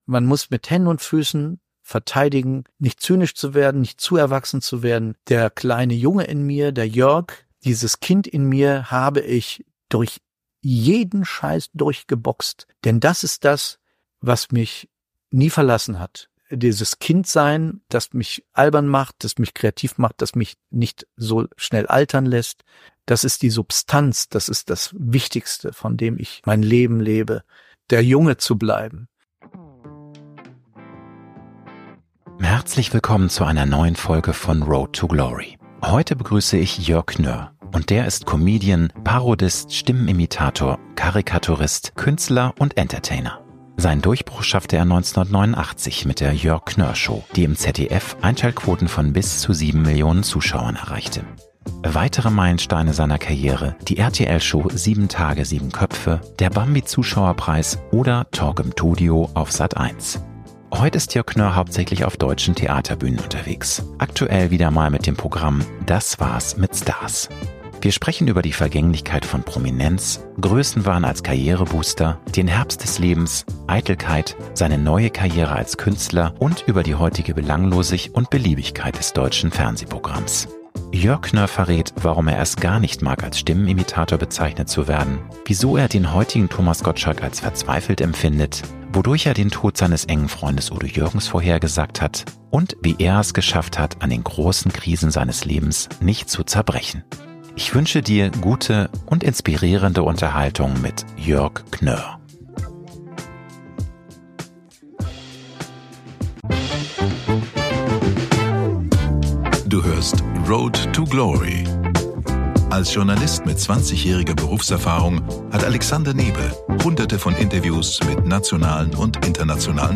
In jeder Episode spricht Alexander Nebe mit einem Star über dessen ganz persönliche Erfolgsstory, Leidenschaften, Ängste, Lebensphilosophien und Kraftpole. Diese Show bietet inspirierende Einblicke in die Erfolgsstrategien, Tools und Tagesroutinen von Top-Performern aus Musik, Film und TV.
… continue reading 189 afleveringen # Gesellschaft # Bildung # Selbstentwicklung # Kino # Alexander Nebe # Road To Glory # Prominent # Erfolg # Schauspieler # Interview # Talk # Berühmtheit